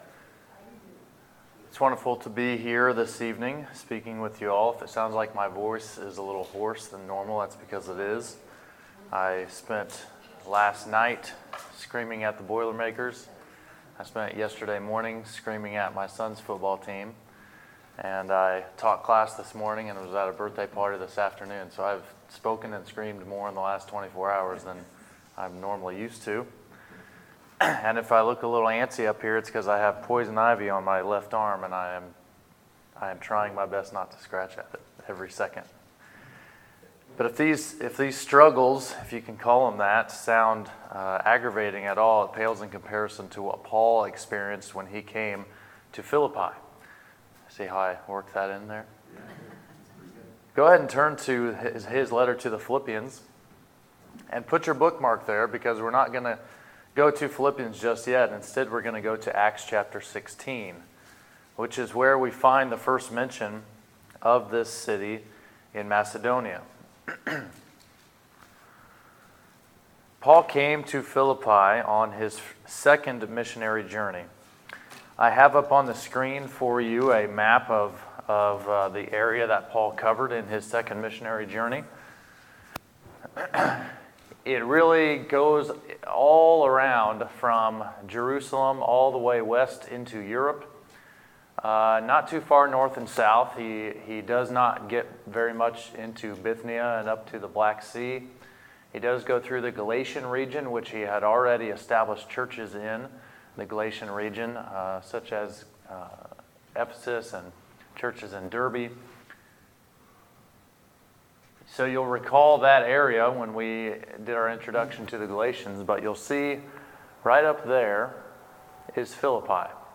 Sermons, September 16, 2018